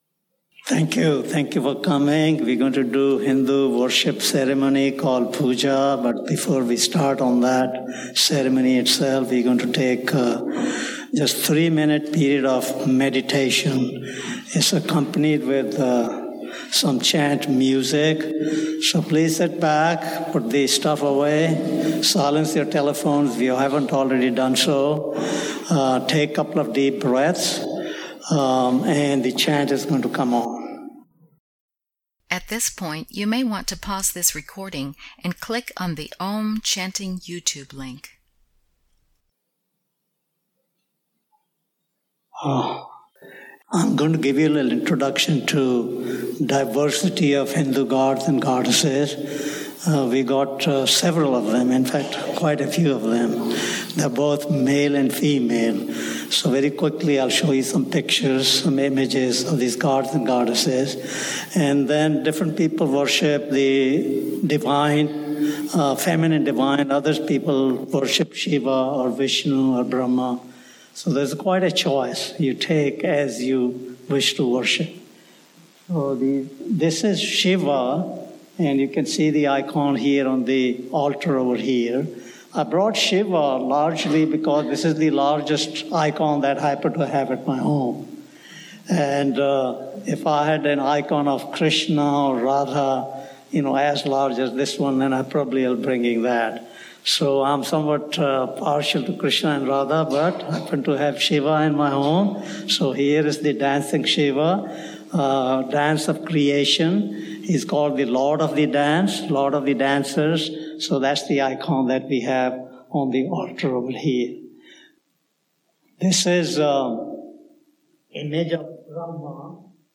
The ceremony consists of the offering of flowers, incense and light to a chosen deity, either male or female. Chanting of a prayer and of sacred mantras accompanies the service.